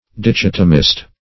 \Di*chot"o*mist\
dichotomist.mp3